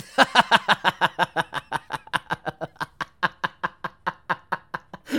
Play, download and share blaugh original sound button!!!!
laughing_MdXEukI.mp3